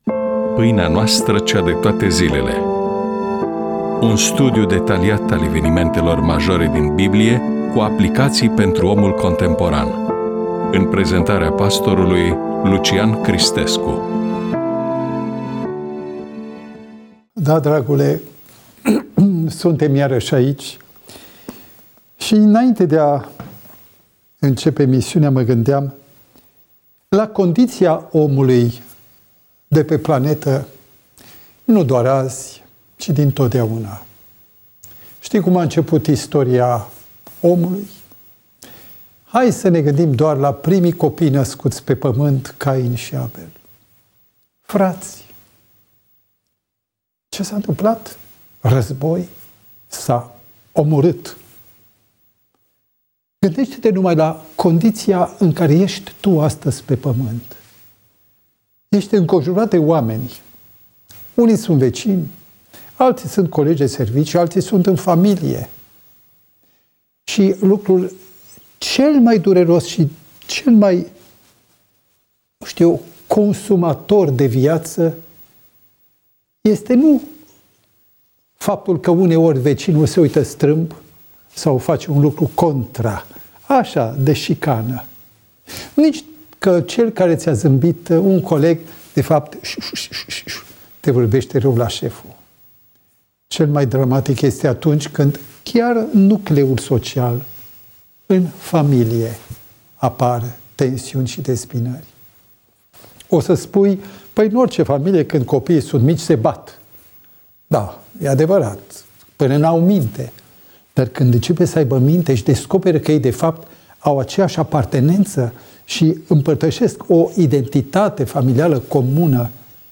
EMISIUNEA: Predică DATA INREGISTRARII: 13.02.2026 VIZUALIZARI: 40